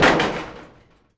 metal_sheet_impacts_05.wav